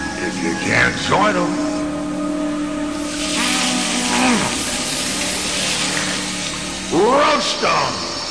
FREDDY KRUEGER- ROBERT ENGLUND